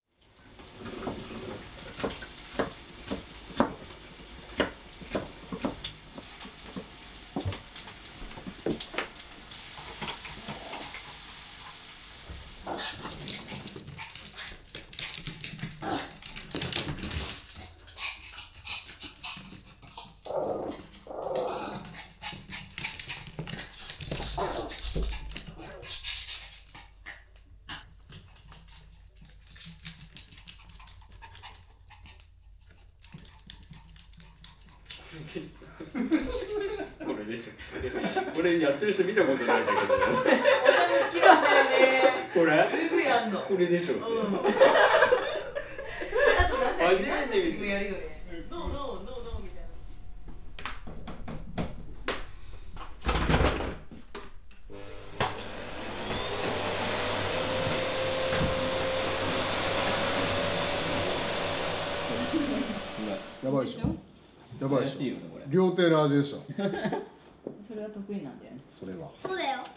防犯用に留守宅で生活音を流してくれるCDです。
1.　家族の会話 part 1  (10:00)    2.　家族の会話 part 2  (10:00)
3.　友達との会話   (10:00)    4.　台所で料理している音   (10:00)
5.　生活音   (10:00)     6.　犬の一人遊び  (10:00)    7.　犬の足音   (10:00)
SACD (dsd)形式で録音、高性能なマイク等を使用しているので、
音がリアルです。